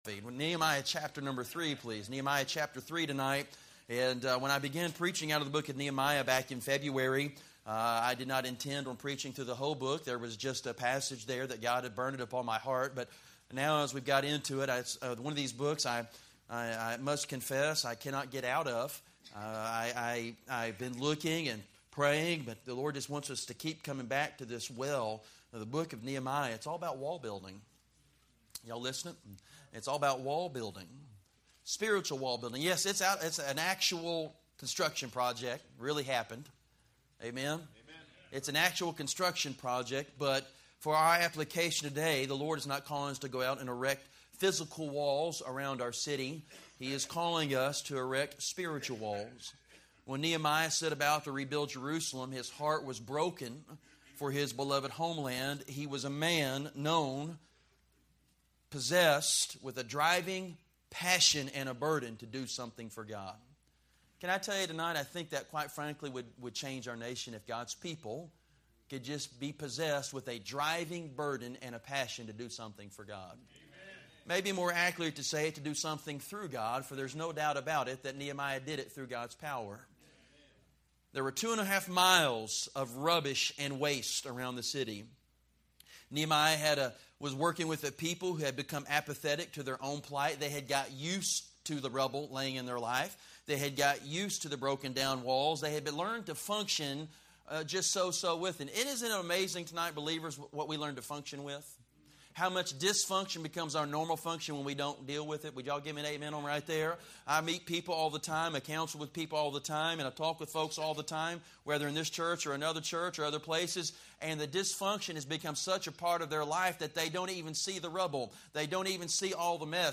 A message from the series "Nehemiah."